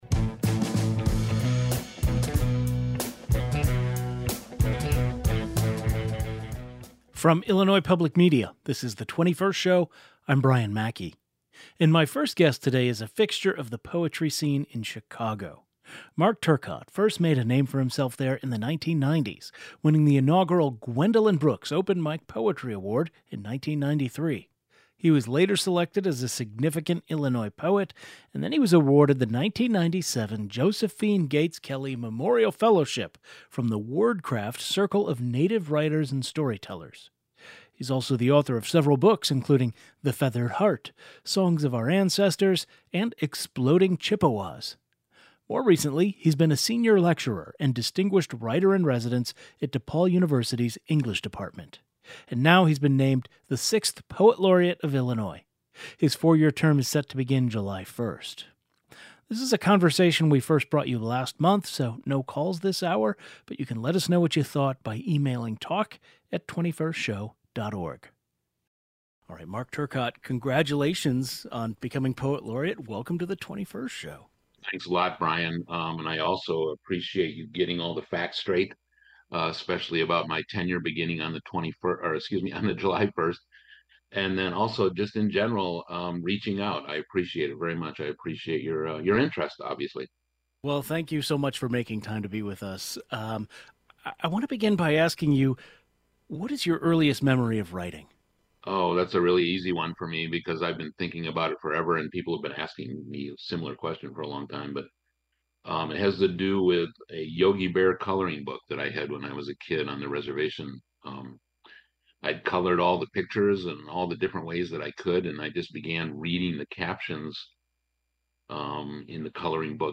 He joins the program to talk about how his lived experiences have shaped his writing, his Native American identity and what he hopes to accomplish as Poet Laureate.